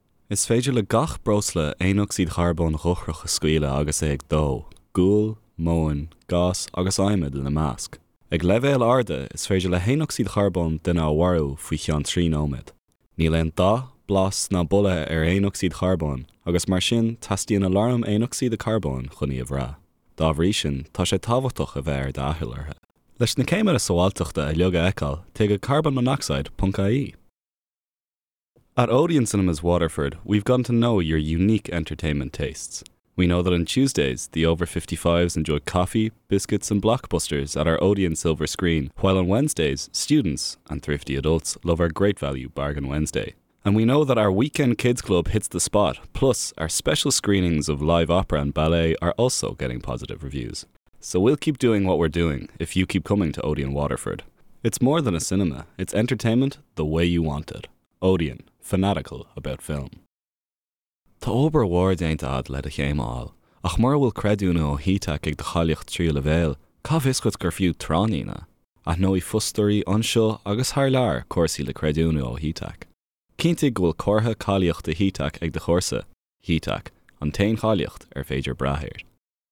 Male
20s/30s
Irish Dublin Neutral, Irish Neutral